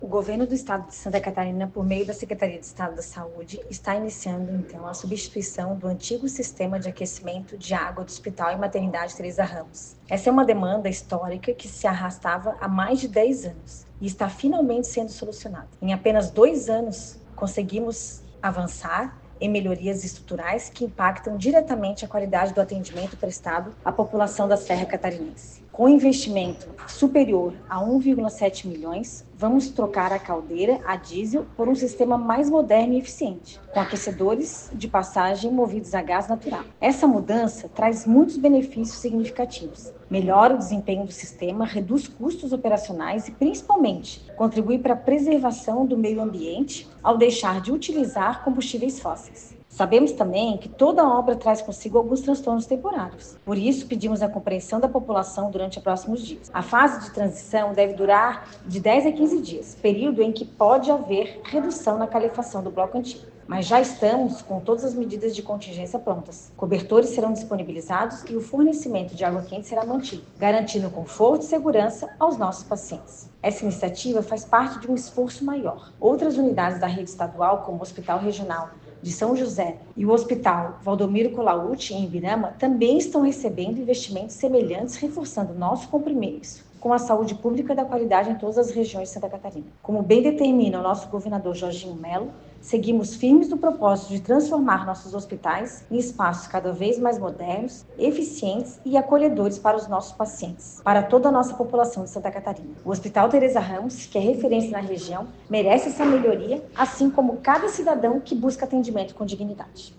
A superintendente dos Hospitais Públicos Estaduais, Tatiana Bez Batti Titericz, explica que todas as providências necessárias estão sendo tomadas para minimizar qualquer impacto, garantindo que os pacientes não sintam os efeitos dessa transição: